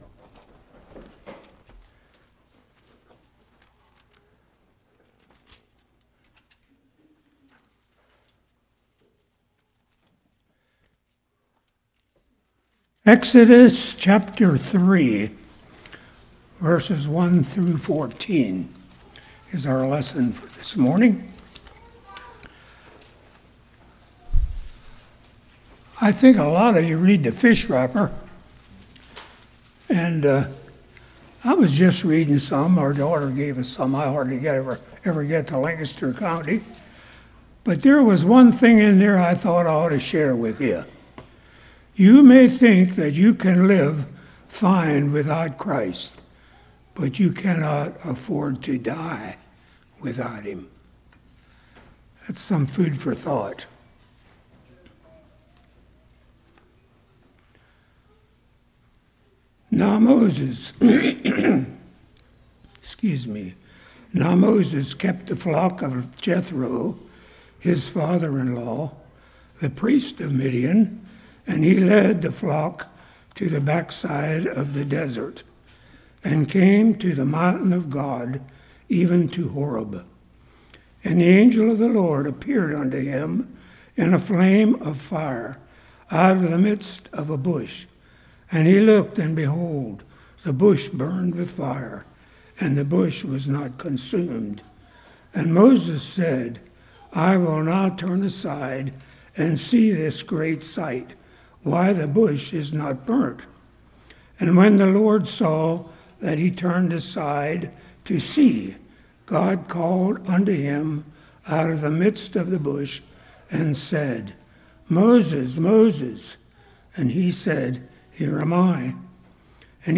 Exodus 3:1-14 Service Type: Morning Hearing God’s Call